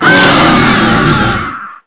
sound / gargoyle / death1.wav
death1.wav